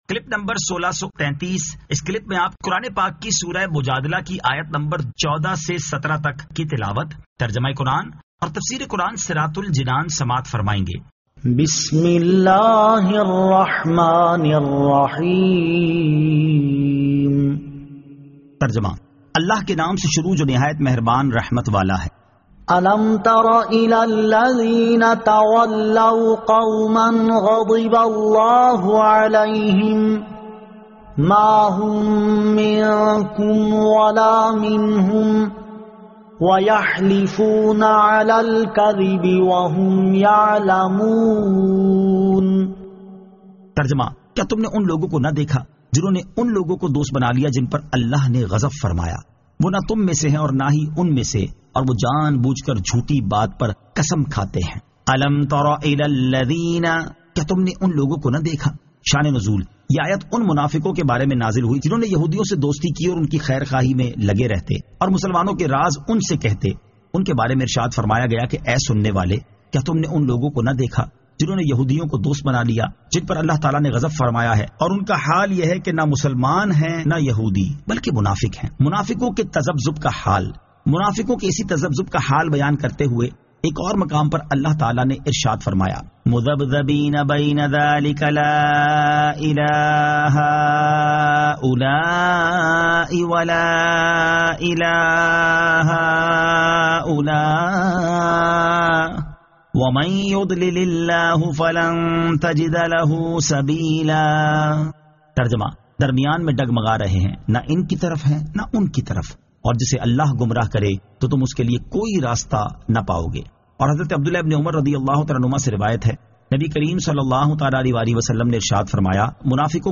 Surah Al-Mujadila 14 To 17 Tilawat , Tarjama , Tafseer